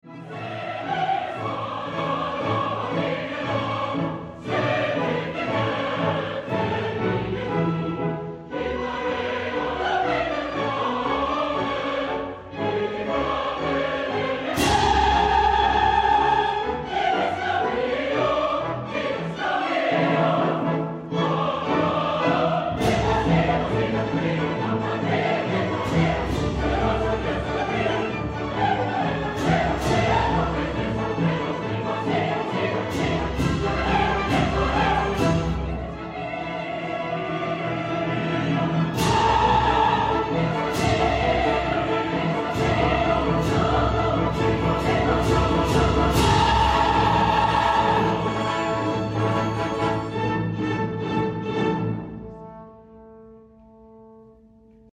Bizet's Carmen (concert version) Opera sound effects free download
at DSO in Feb '25